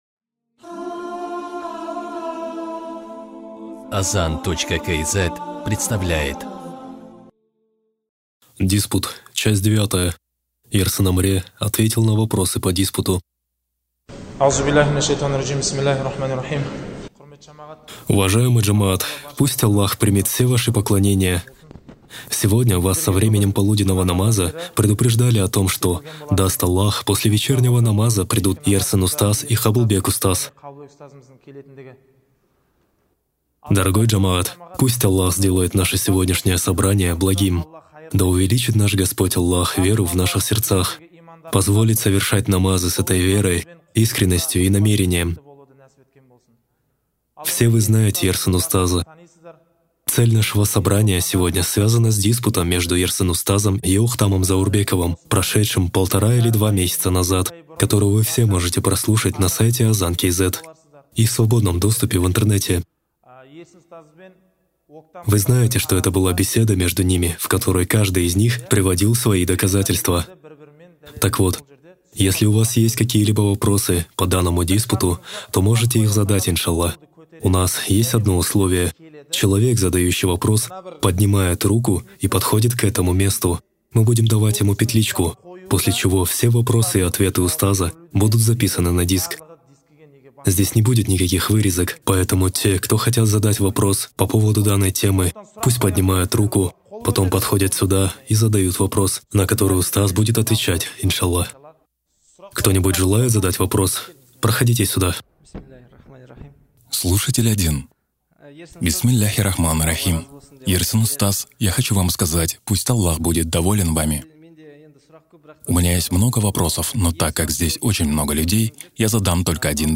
Духовное управление мусульман Казахстана, с целью борьбы с деструктивными течениями выбрало наиболее безопасный и благородный метод - научный диспут по акыде, где главным оружием является сила довода и сила разума.